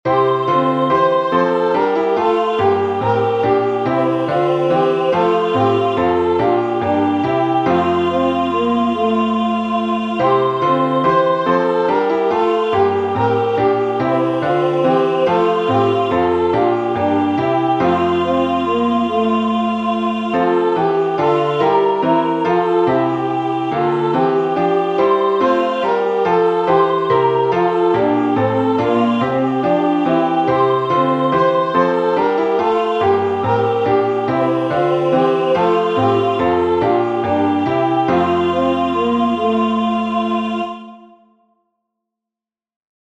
Acclamation (UK 24)Show us O Lord